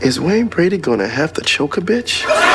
WB Choke